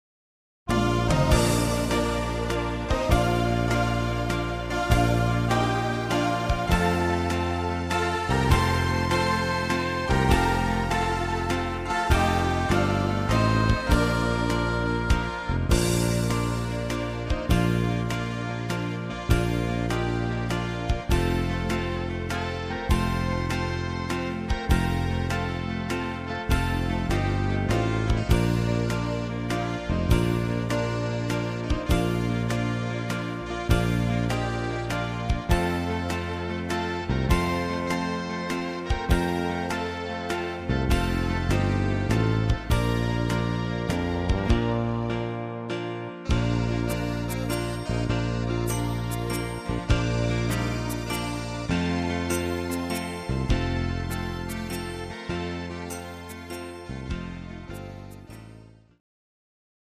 instr. Saxophon